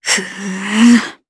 Valance-Vox_Casting1_jp.wav